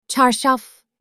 ملحفه به ترکی استانبولی: Çarşaf (چارشاف)
bedsheet-in-turkish.mp3